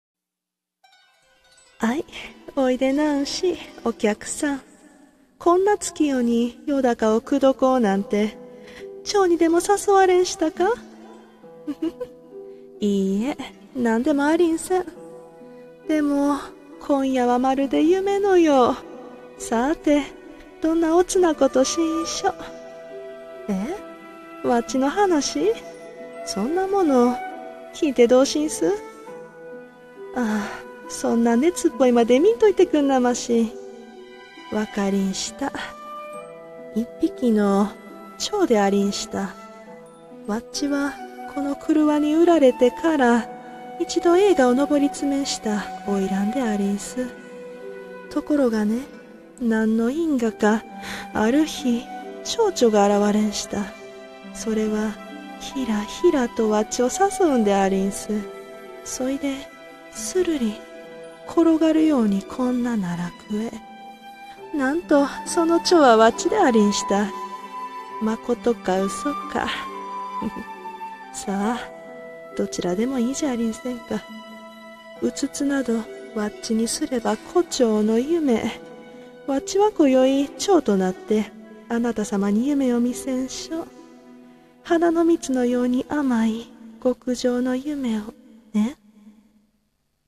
【一人声劇】胡蝶の夢